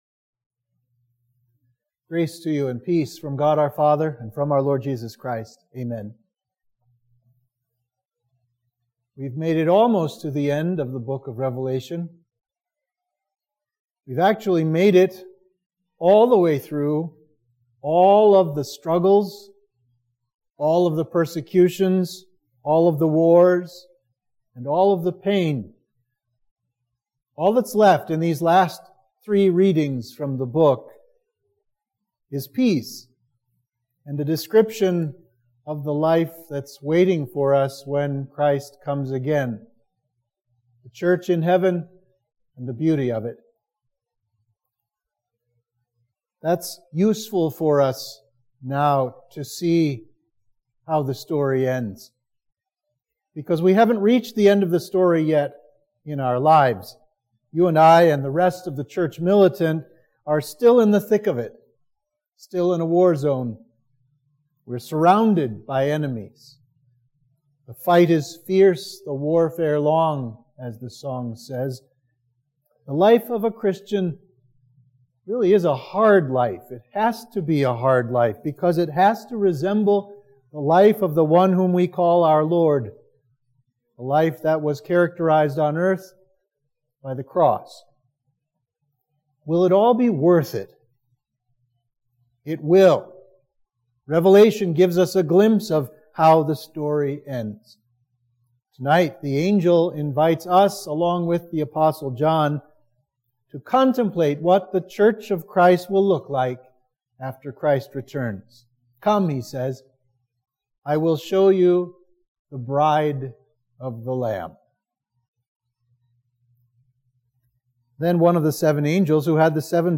Sermon for Midweek of Trinity 25